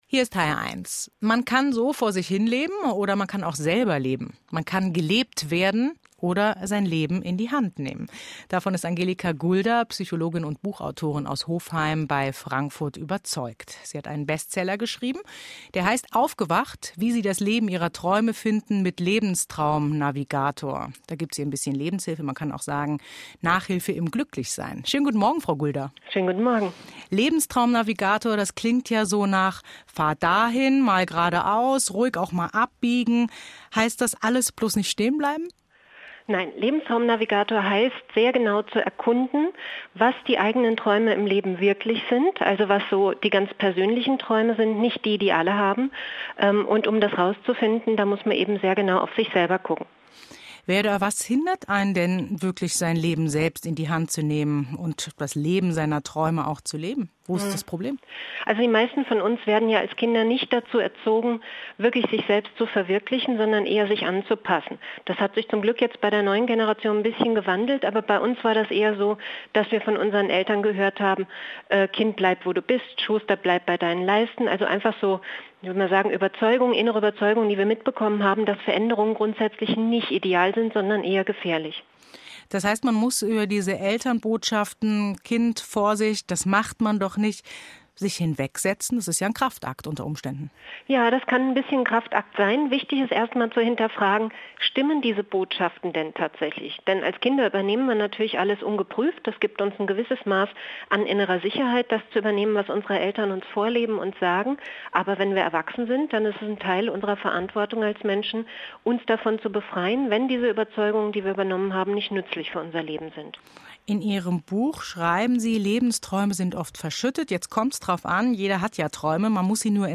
Live-Interview